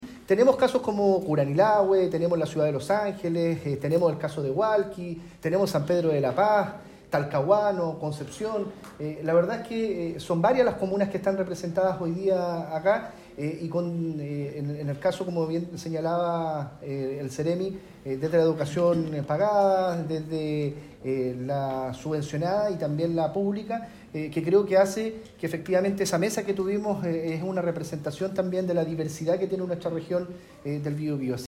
Mientras desayunaban en un mesón amplio en el Salón Biobío de la Delegación Presidencial Regional, las autoridades preguntaron a las y los estudiantes a qué carreras y universidades quieren postular, lo que permitió constatar que la mayoría tiene intenciones de quedarse en la Región del Biobío.
Finalmente, el delegado presidencial, Eduardo Pacheco, destacó que los jóvenes distinguidos provengan de distintas comunas.